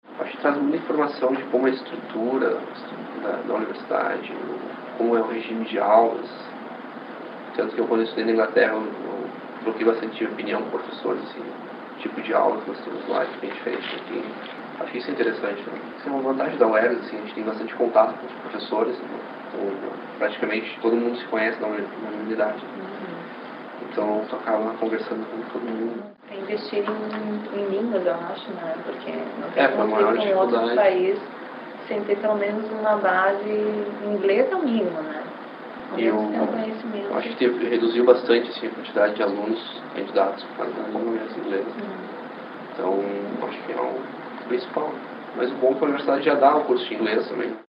>> Sonora dos alunos comentando sobre a importância da Uergs nesse novo processo